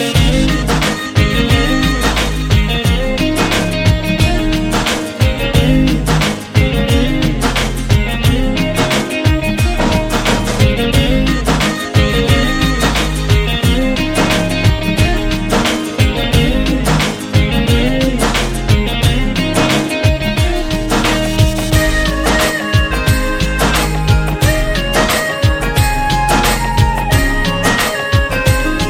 Category: Bollywood Ringtones